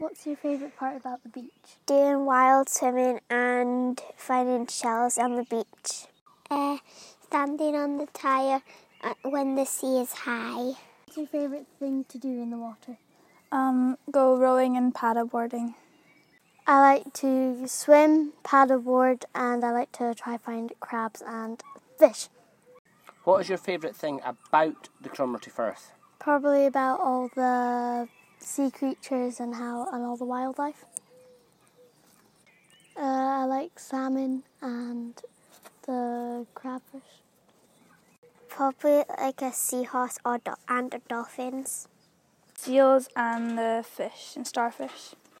We worked with Cromarty Youth Cafe to record local people’s thoughts about the changes that are happening in our firth. The Youth Cafe came up with interview questions, and posed them to each other and to members of the local community.